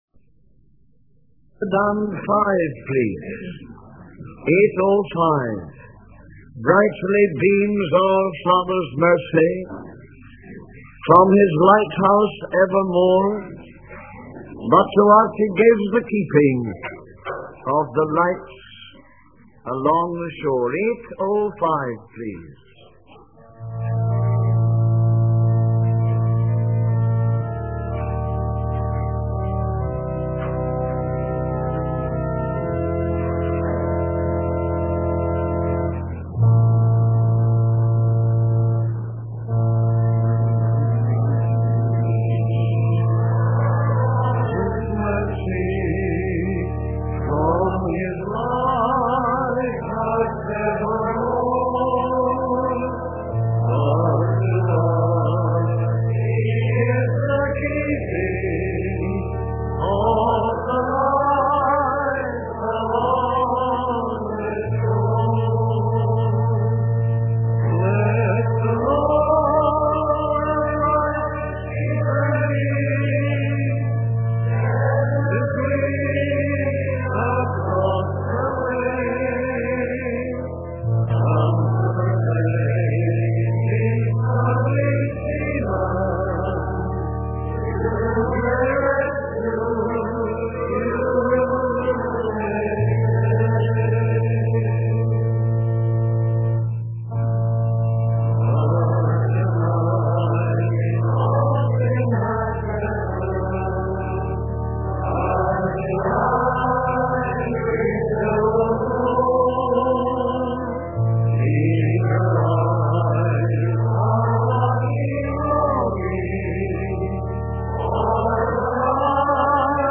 The preacher encourages the listeners to pay attention to what the Spirit is saying to the churches.